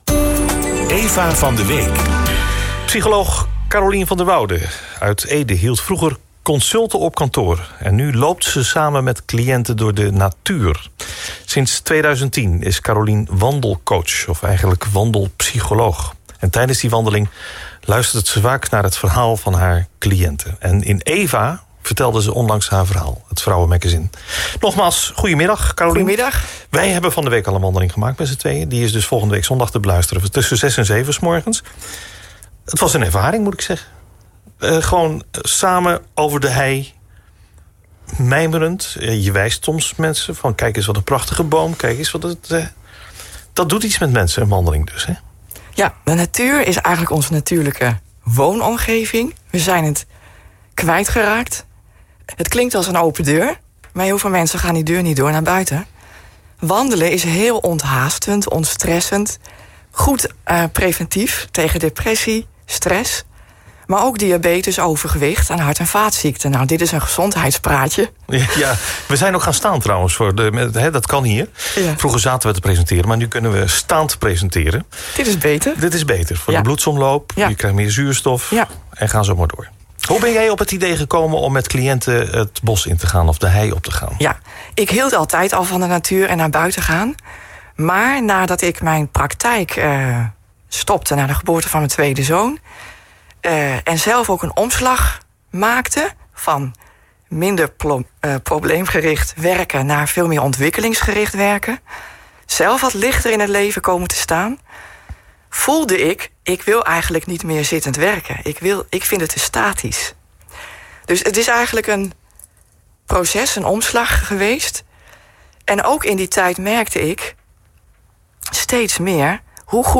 wandelpsycholoog-studiogesprek-chr.mp3